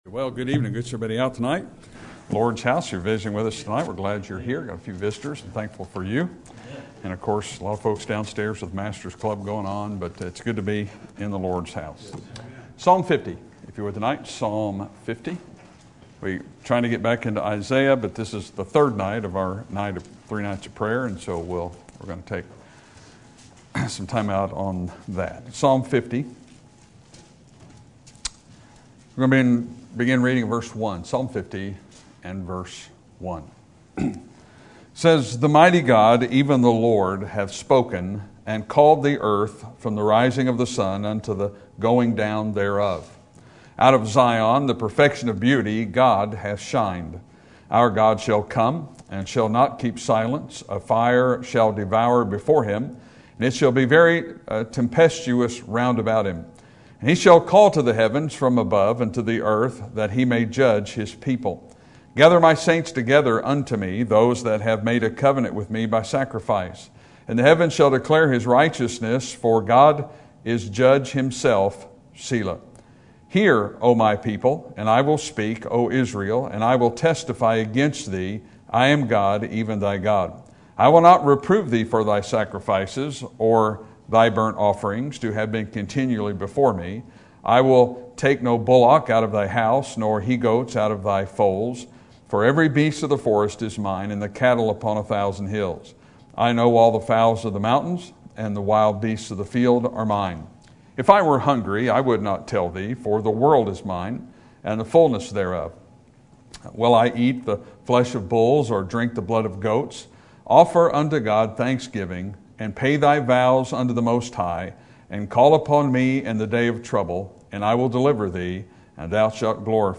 Sermon Topic: Prayer Sermon Type: Series Sermon Audio: Sermon download: Download (30.56 MB) Sermon Tags: Psalm Prayer Formalism Obedience